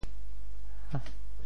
「簟」字用潮州話怎麼說？
簟 部首拼音 部首 竹 总笔划 18 部外笔划 12 普通话 diàn 潮州发音 潮州 diem6 文 潮阳 diam6 文 澄海 diang6 文 揭阳 diam6 文 饶平 diam6 文 汕头 diam6 文 中文解释 潮州 diem6 文 对应普通话: diàn ①供坐臥鋪墊用的葦蓆或竹蓆：竹～ | ～子（簟席。